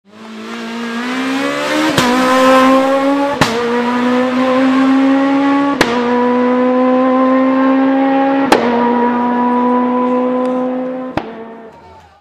Renault clio sport sound ringtone free download
Message Tones